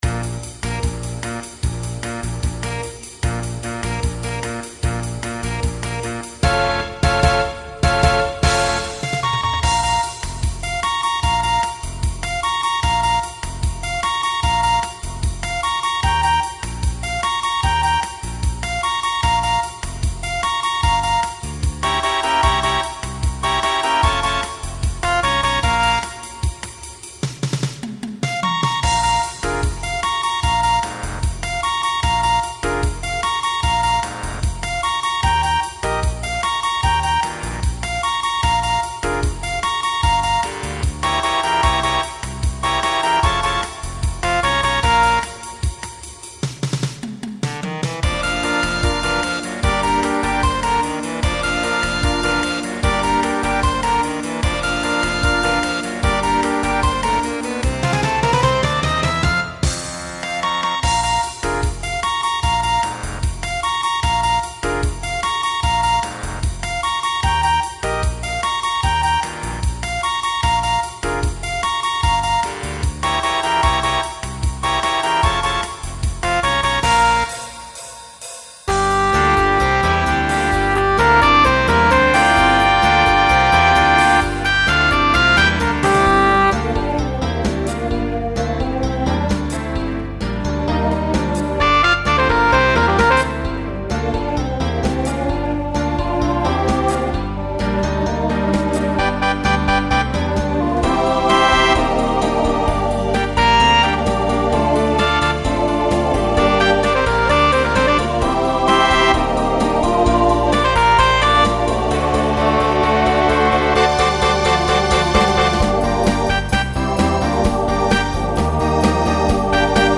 Voicing SATB Instrumental combo Genre Broadway/Film , Rock
Mid-tempo